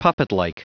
Prononciation du mot puppetlike en anglais (fichier audio)
Prononciation du mot : puppetlike